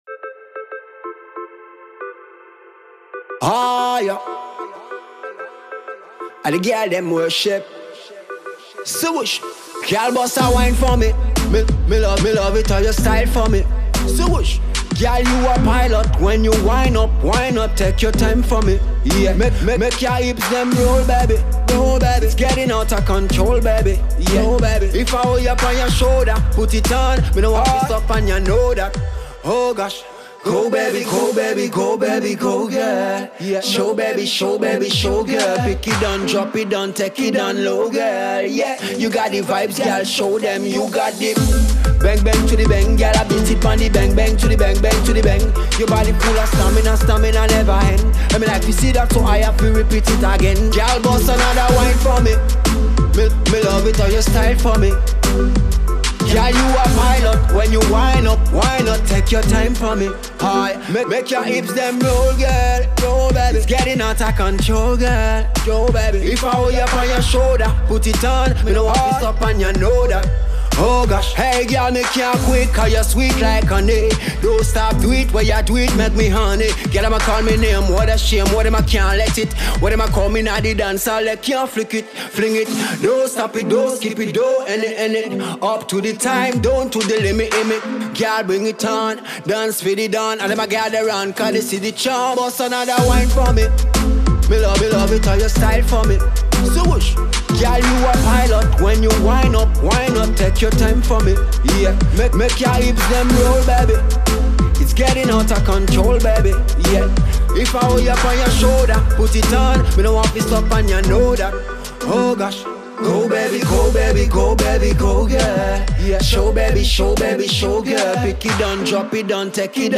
le chanteur de reggae-dancehall guadeloupéen